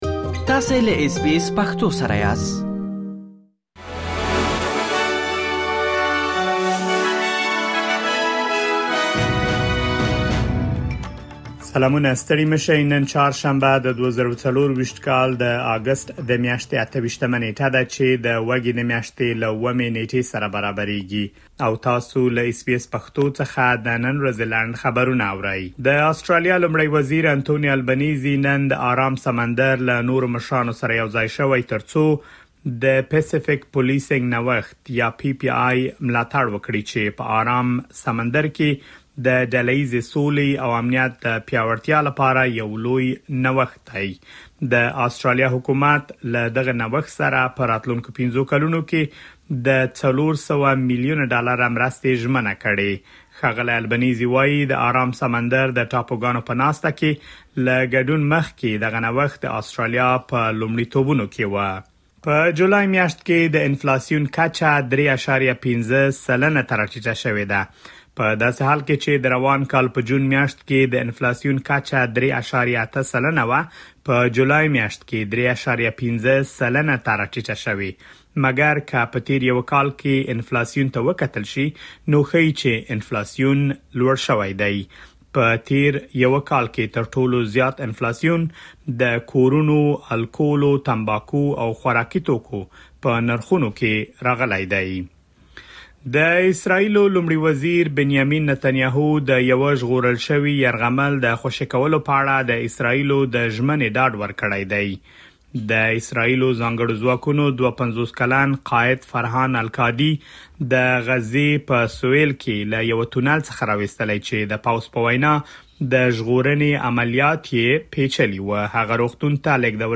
د اس بي اس پښتو د نن ورځې لنډ خبرونه|۲۸ اګسټ ۲۰۲۴
د اس بي اس پښتو د نن ورځې لنډ خبرونه دلته واورئ.